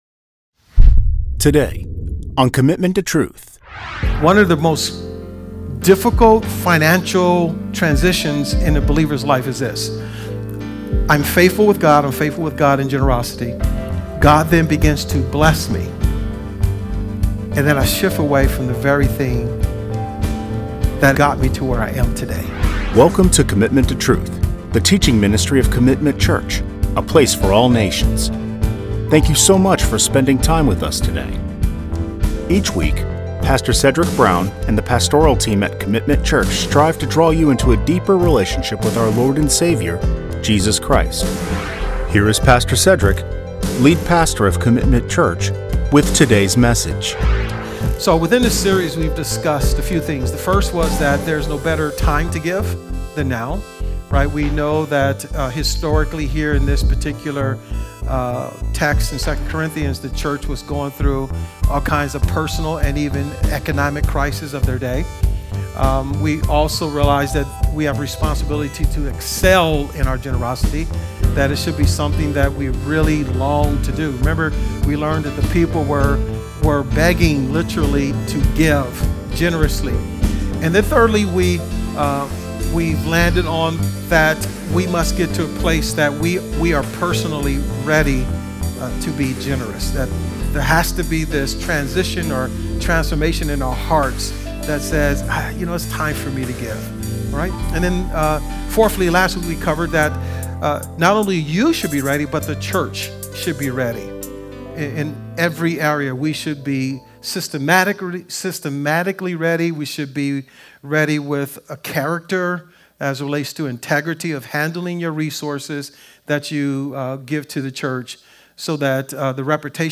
In this sermon series, our pastoral team will remind and instruct the Church that our collective generosity will always be needed to continue advancing the Gospel of Jesus to all nations, into the next generation, and until Christ returns.